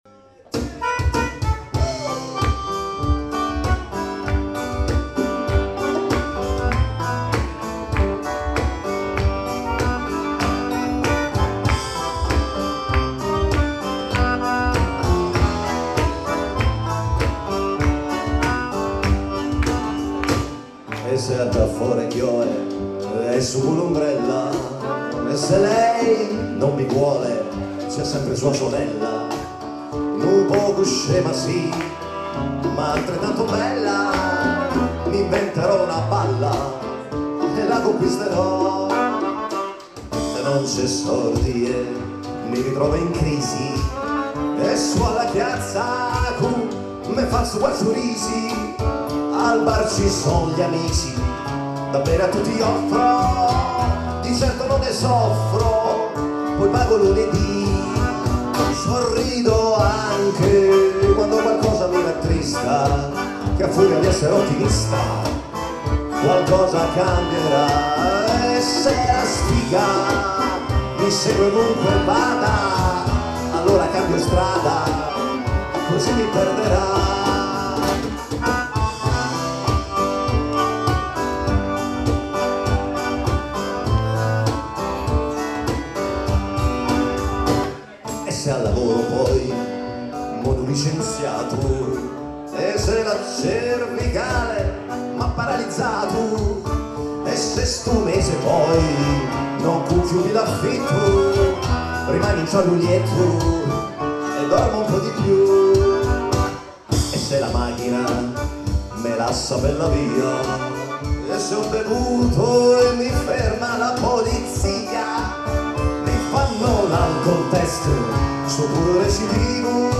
voce, piano, chitarra acust.
fisarmonica, piano
del concerto (tracce separate)